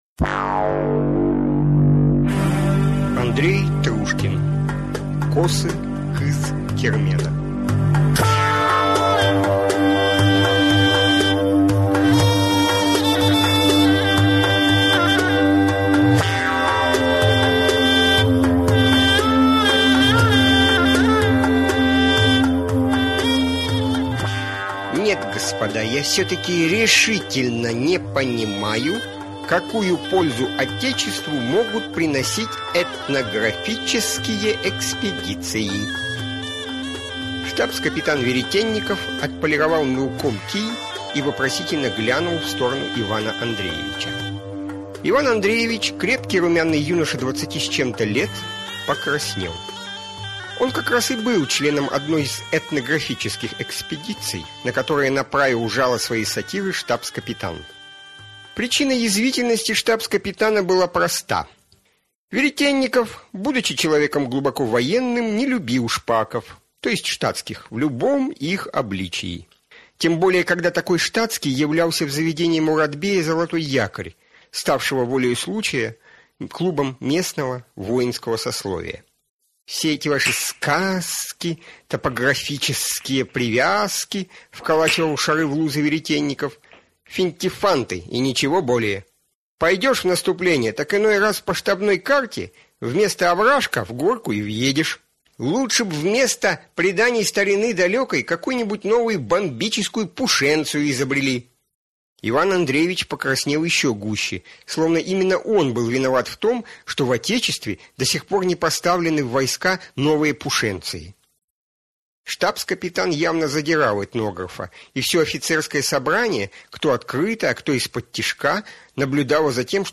Аудиокнига Косы Кыз-Кермена. Случай на Крымской войне | Библиотека аудиокниг